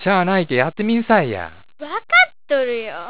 ためになる広島の方言辞典 さ．